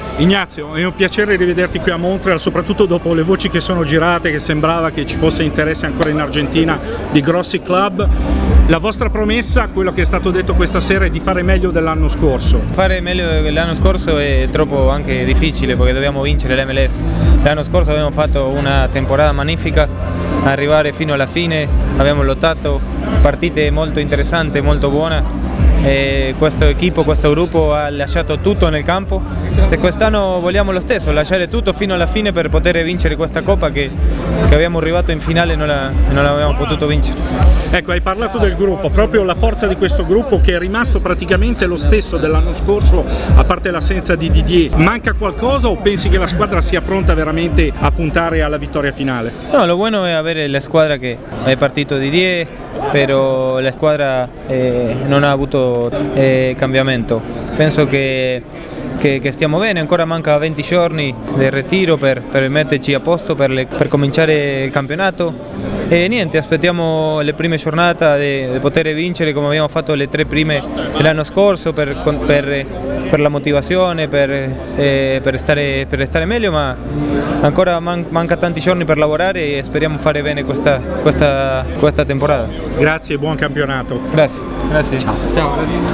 Stagione 2017: prospettive e considerazioni dei protagonisti all’assemblea dei membri e presentazione della seconda maglia per la stagione 2017
Le interviste: